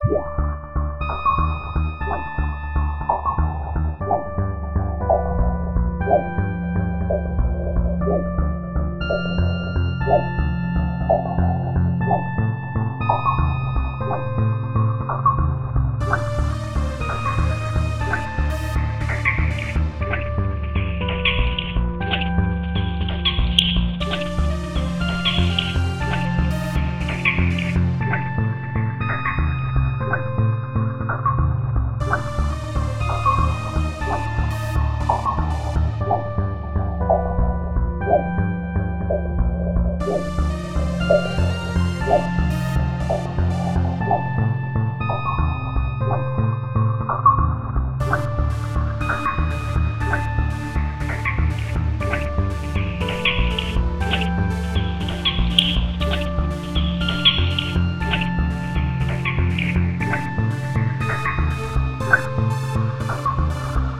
Intense water level music.